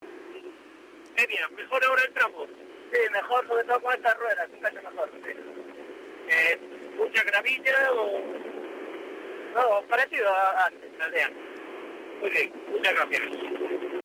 Declaraciones de los pilotos, cortesía de Cadena Dial: